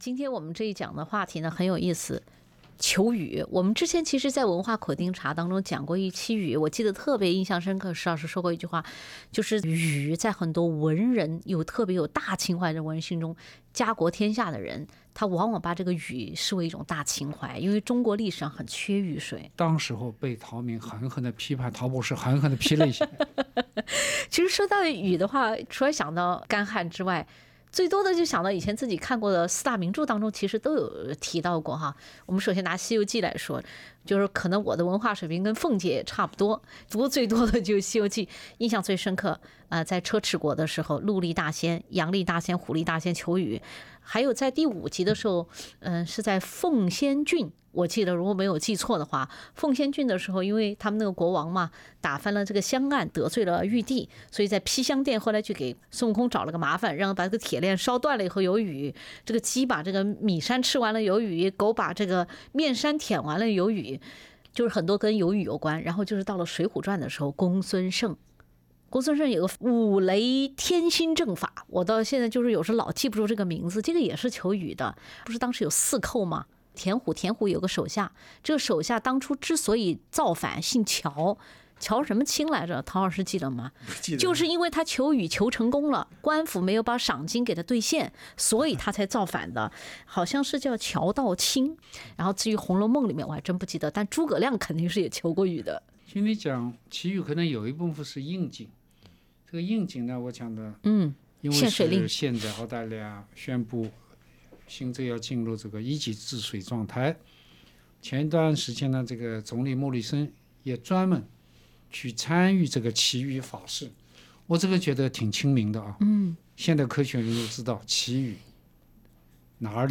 欢迎收听SBS 文化时评栏目《文化苦丁茶》，本期话题是：求雨（第一集）-皇上的“日光浴”（全集）请点击收听。